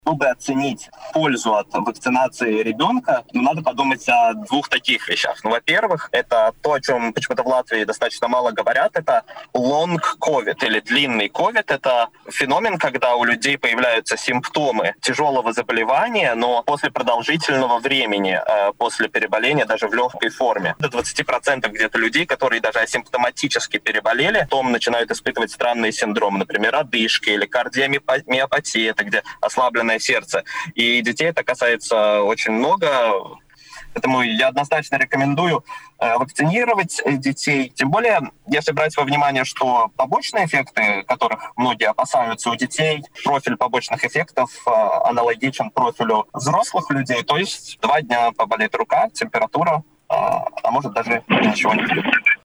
Побочные эффекты прививок от COVID-19 у детей совпадают с возможными последствиями у взрослых – но при этом вакцинация подростков предотвратит возможные серьезные осложнения после болезни и уменьшит риск распространения коронавируса. Об этом в эфире радио Baltkom рассказал представитель отдела вакцинации Национальной службы здоровья